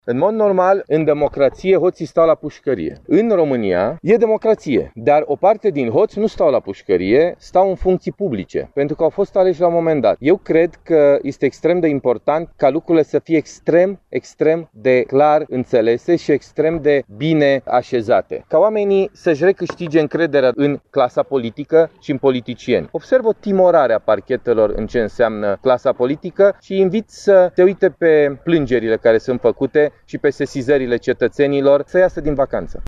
Prezent la Piatra Neamţ, pentru a-şi susţine colegii de partid în campania electorală de la alegerile locale din această lună, europarlamentarul PNL, Rareș Bogdan, a îndemnat oamenii legii să iasă din vacanţă şi să se apuce serios de treabă în ceea ce priveşte clasă politică şi cei din administraţia publică, acuzaţi de încălcarea legii: